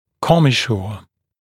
[‘kɔmɪʃuə][‘комисйуэ]спайка, соединение, комиссура